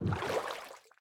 paddle_water3.ogg